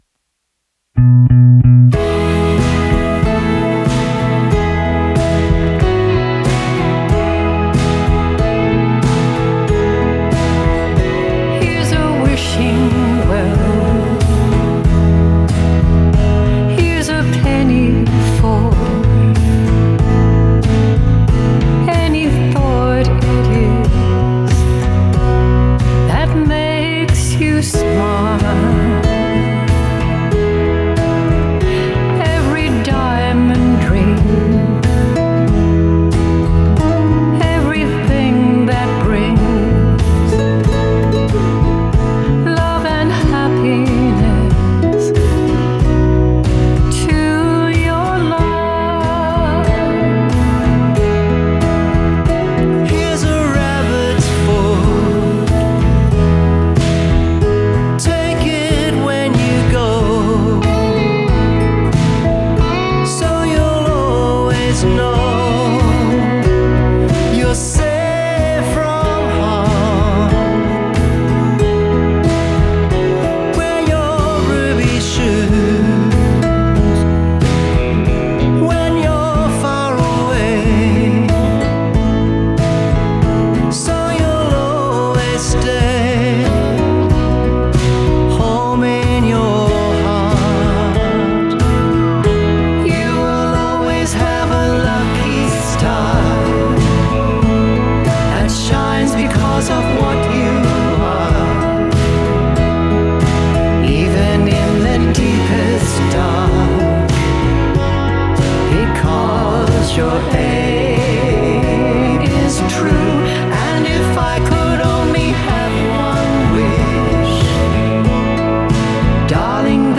This beautiful duet